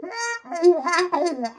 吆喝
描述：在痛苦或恐怖中大喊大叫
Tag: 大呼小叫